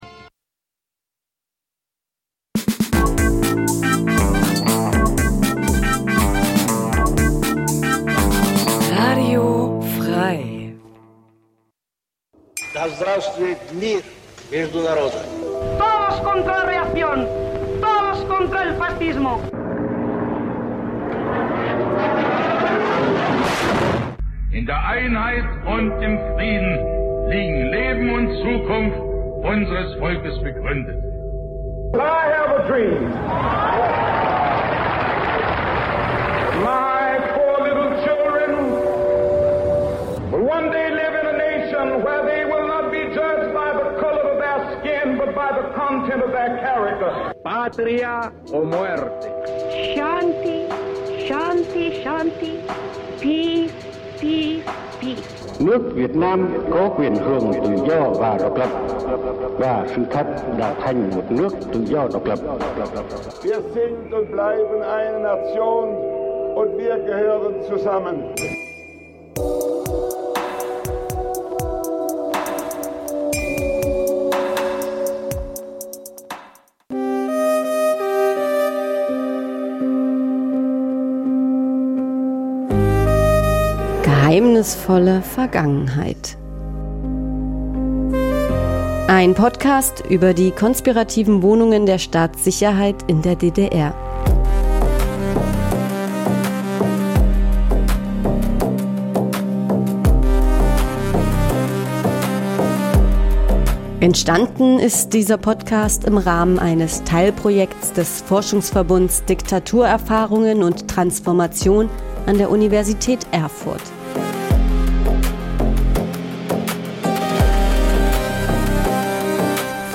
Programm von Kindern und Jugendlichen für Kinder und Jugendliche Dein Browser kann kein HTML5-Audio.